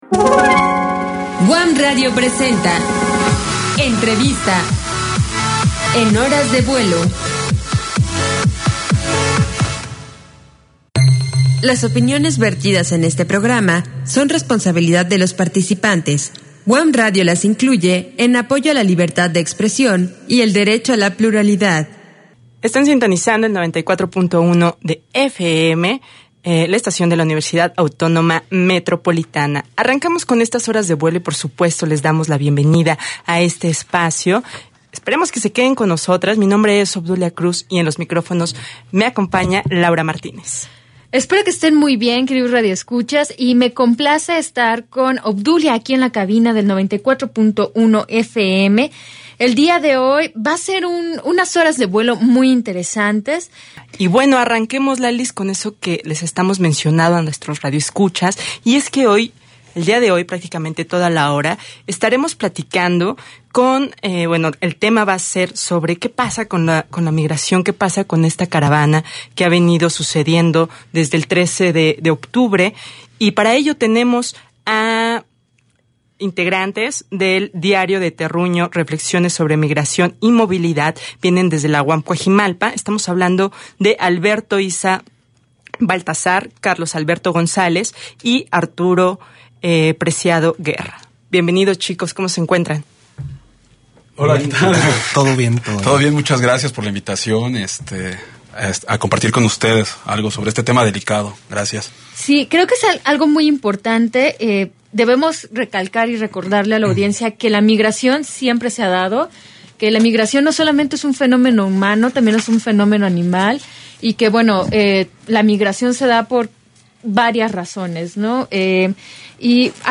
Escucha el Podcast de Diarios del Terruño en UAM Radio charlando sobre la Caravana Migrante